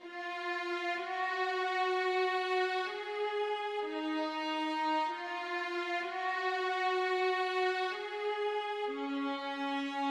描述：管弦乐循环 曼哈顿
标签： 95 bpm Hip Hop Loops Orchestral Loops 1.70 MB wav Key : Unknown
声道立体声